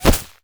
bullet_impact_grass_06.wav